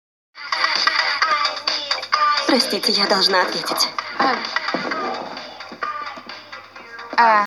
Что тут за барабанЬчики?